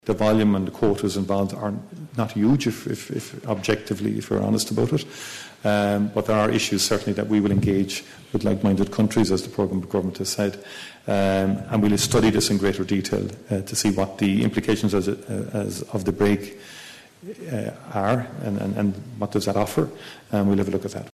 Taoiseach Micheál Martin says the full detail of that idea isn’t yet clear…………………